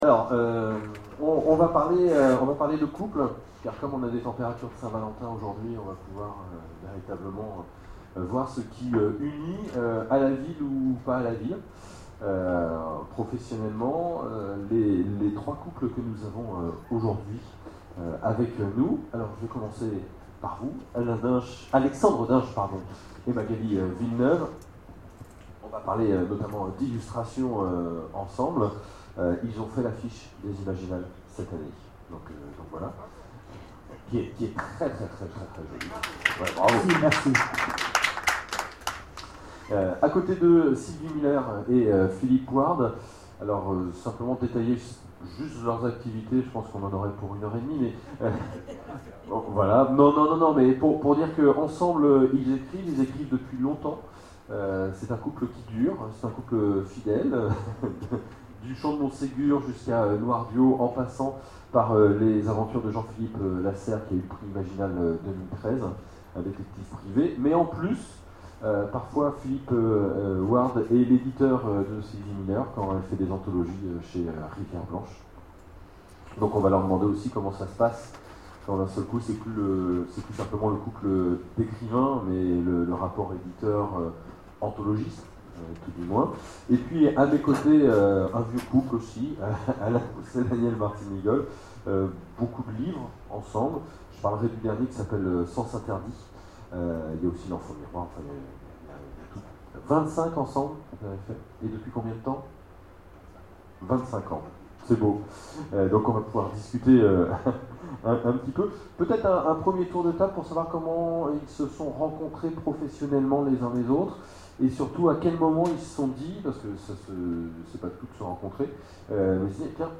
Imaginales 2013 : Conférence A la scène ou à la ville